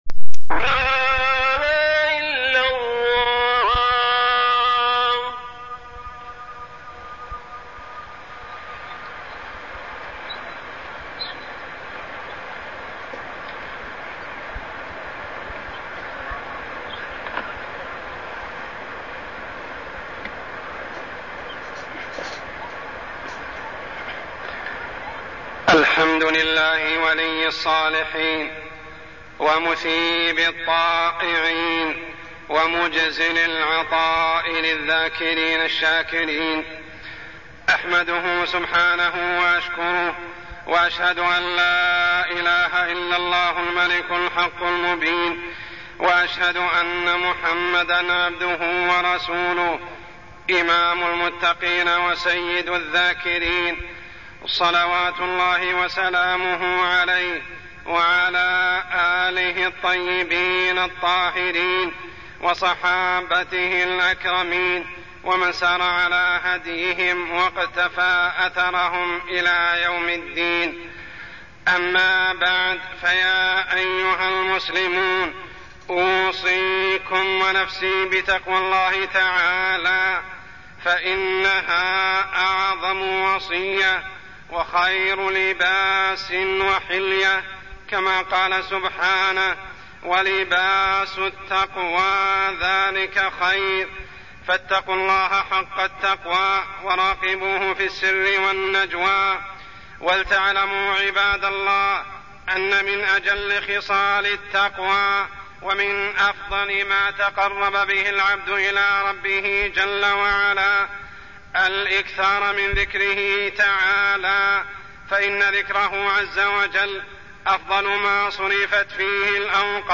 تاريخ النشر ٢ جمادى الأولى ١٤٢٠ هـ المكان: المسجد الحرام الشيخ: عمر السبيل عمر السبيل الذكر